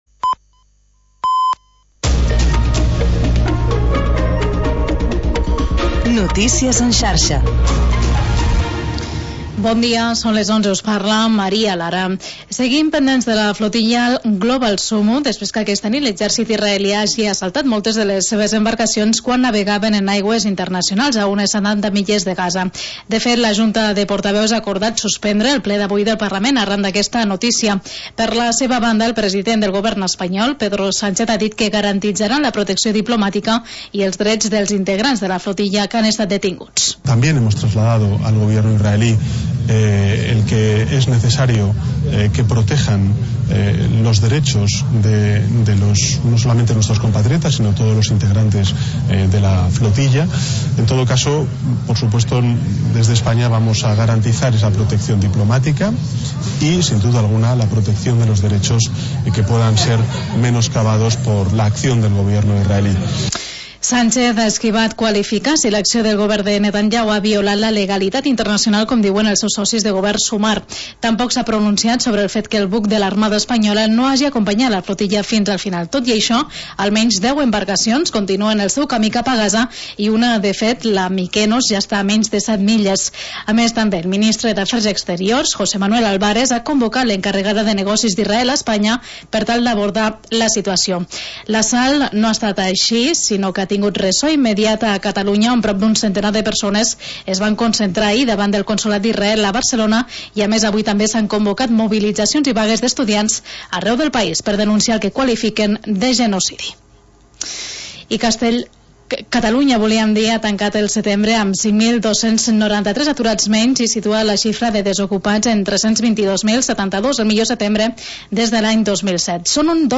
Magazín d'entreteniment per encarar el dia